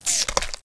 rifle_reload.wav